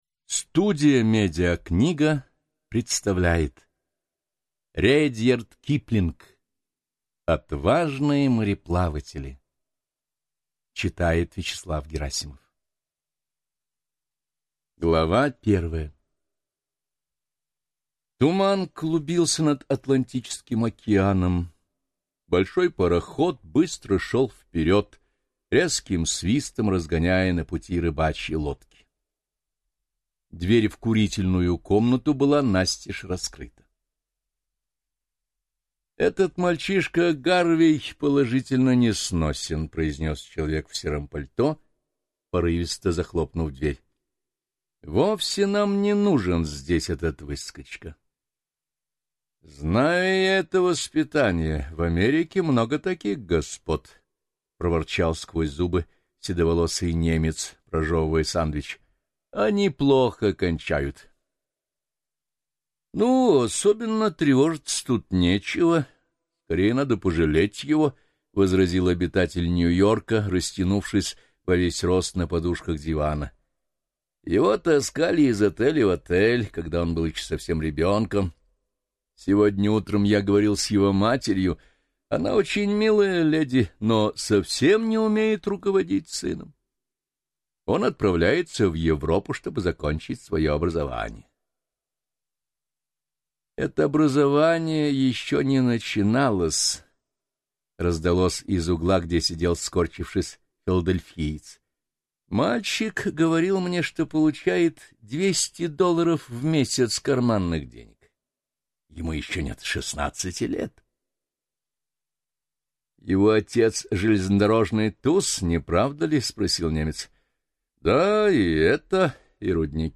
Аудиокнига Отважные мореплаватели | Библиотека аудиокниг